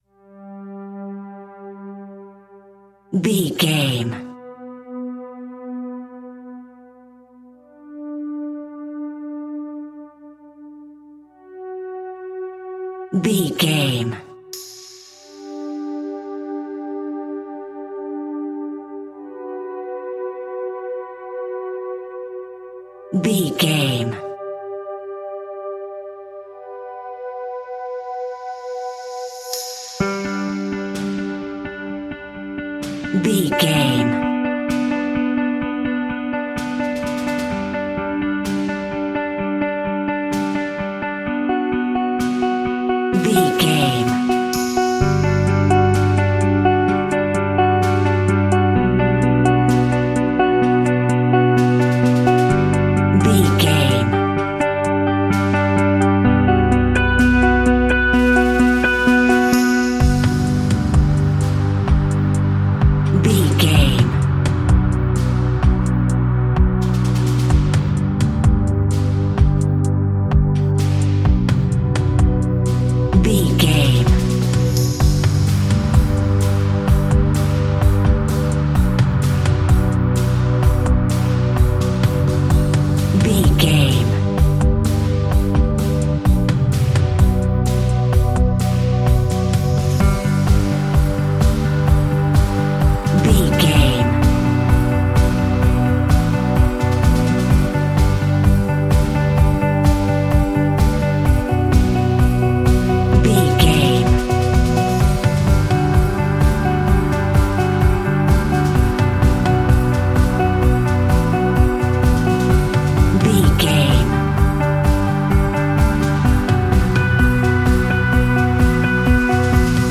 Ionian/Major
Fast
bright
dreamy
epic
driving
uplifting
bass guitar
electric guitar
synthesiser
drum machine
drums